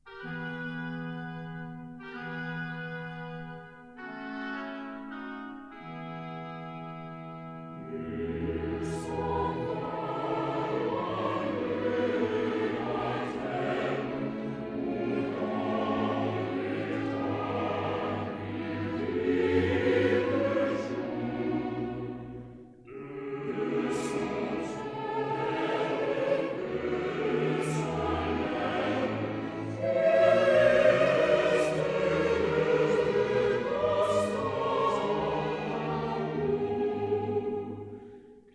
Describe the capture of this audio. Stereo recording made in London